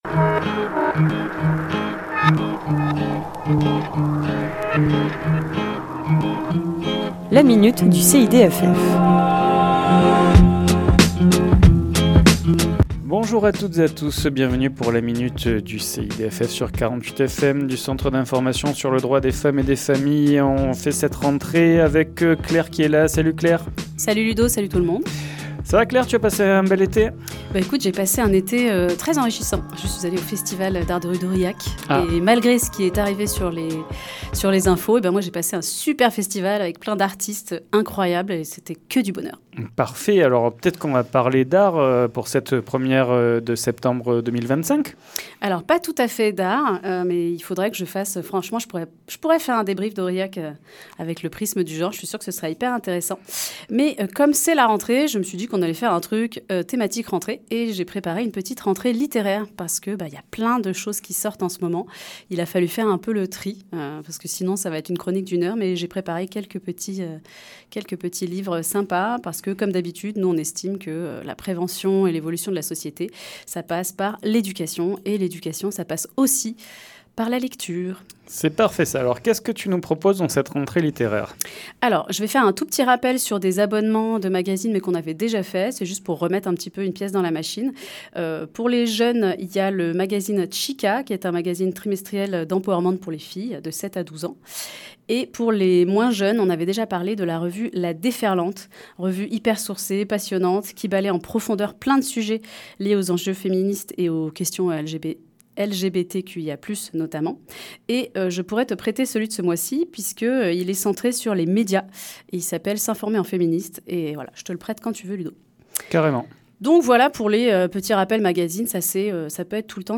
Chronique diffusée le lundi 1er septembre à 11h00 et 17h10